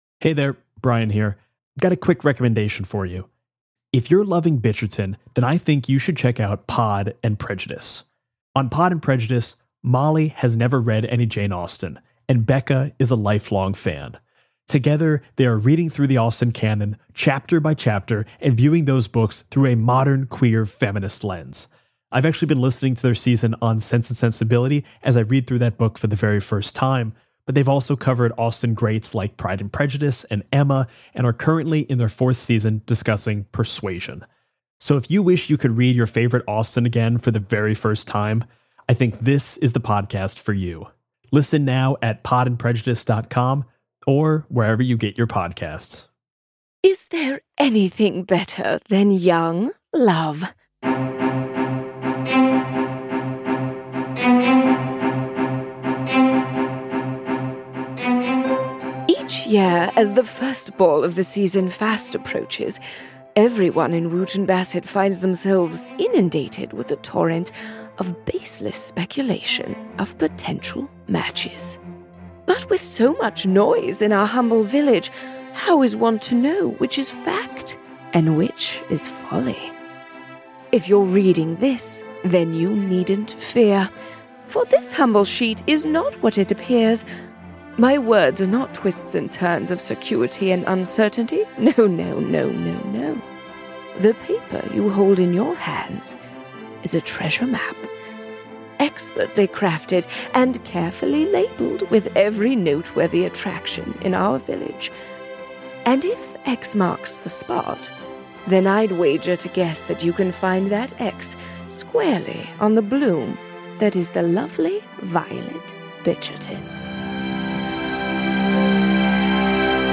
An improvised comedy podcast satirizing the world of Jane Austen that tells the tale of a wealthy family's scandals and shenanigans.